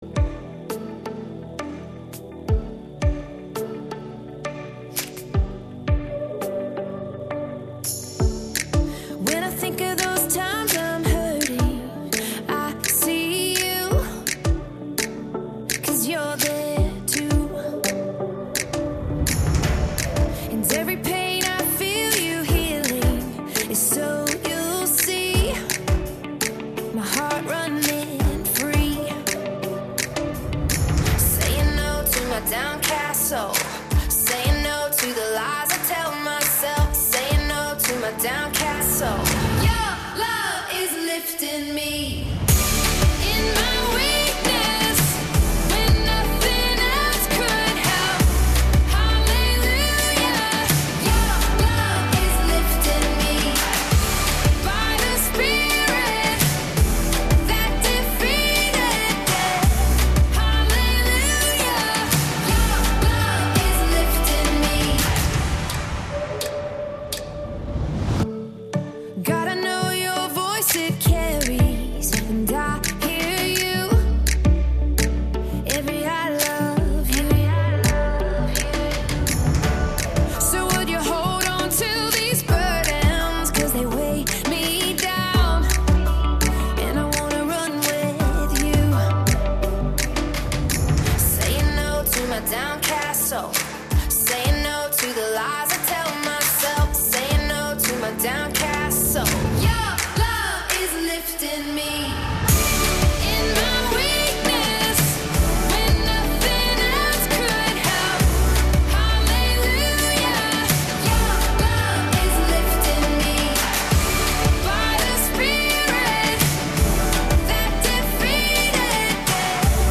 FaithMatters Music to uplift inspire or just bring a point of difference